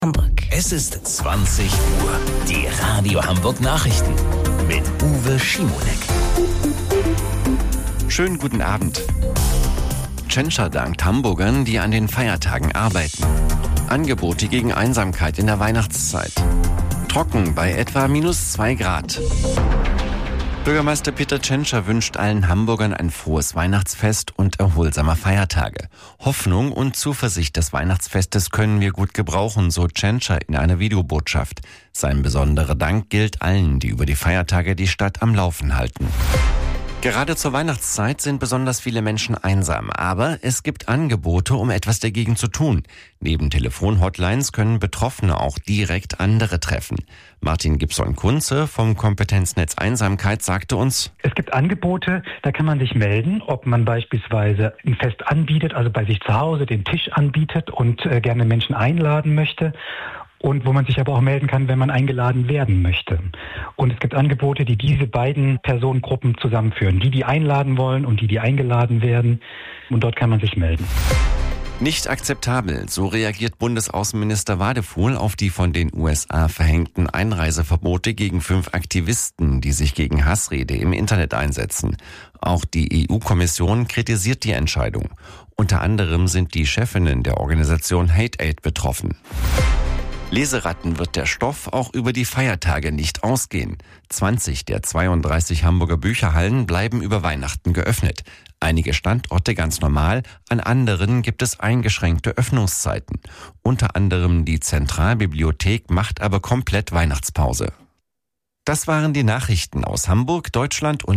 Radio Hamburg Nachrichten vom 24.12.2025 um 20 Uhr